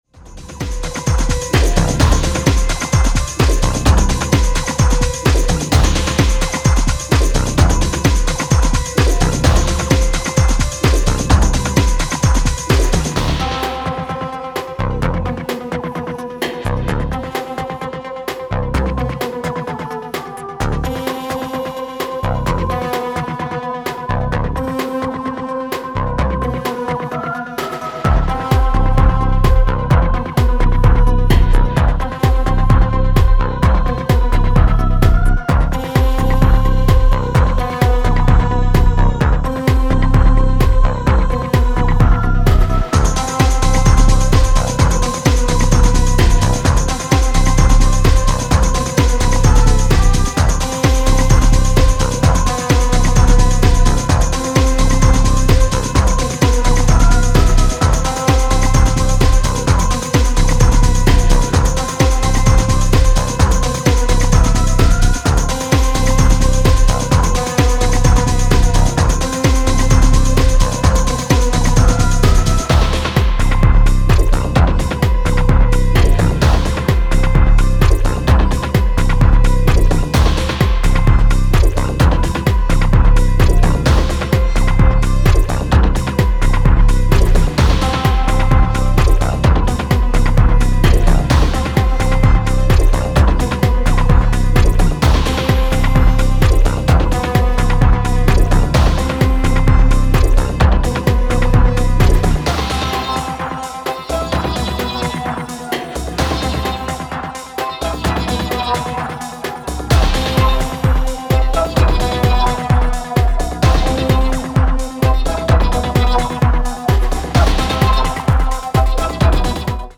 certified dancefloor hits
House Techno Acid